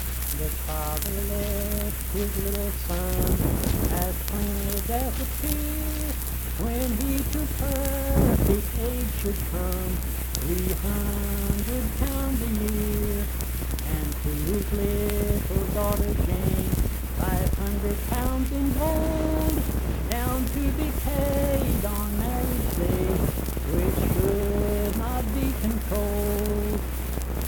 Accompanied (guitar) and unaccompanied vocal music
Verse-refrain 2(4).
Performed in Mount Harmony, Marion County, WV.
Voice (sung)